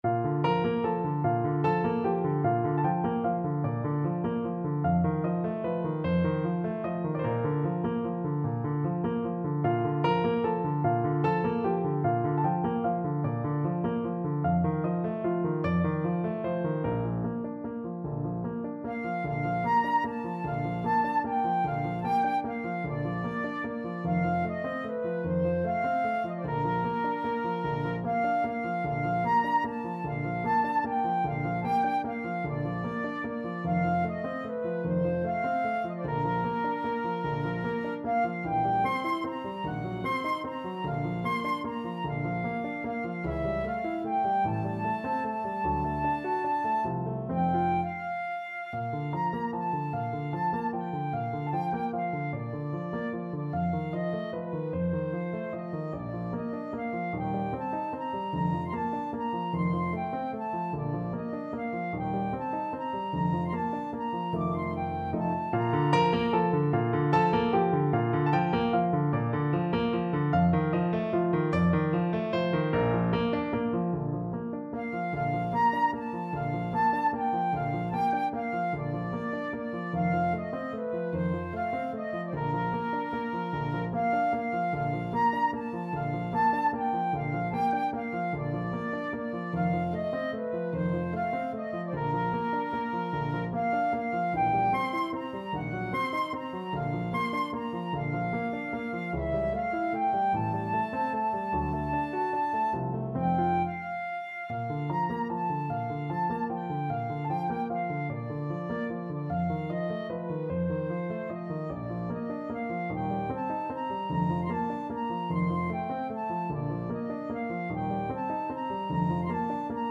Flute
Andantino . = c.50 (View more music marked Andantino)
3/8 (View more 3/8 Music)
Bb major (Sounding Pitch) (View more Bb major Music for Flute )
Classical (View more Classical Flute Music)